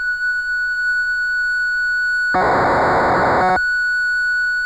Computer Data Signals (Tomita)
また、１曲目と９曲目に当時のビットストリームによるメッセージが隠されている。
９曲目は、１５０文字程度のメッセージらしい。ビットレートは当時のマイコンのデータをテープにセーブする時のスピードだと思われる。